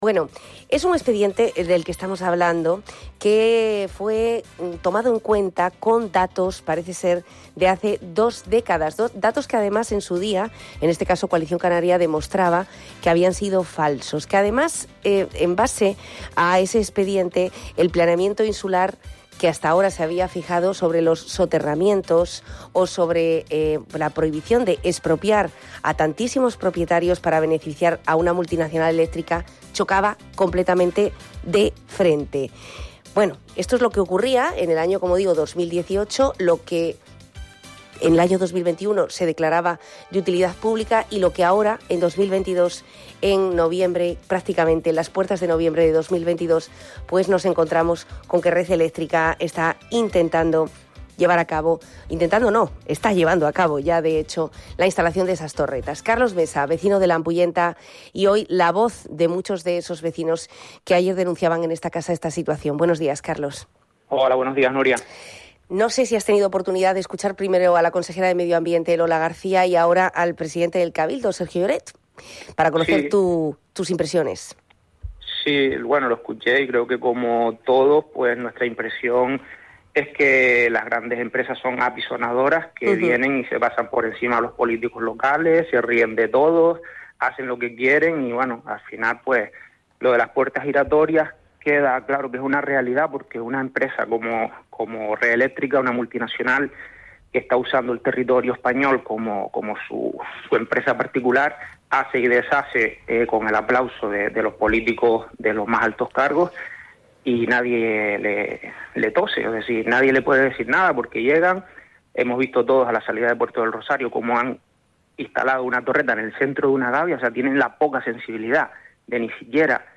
Así lo ha manifestado un portavoz.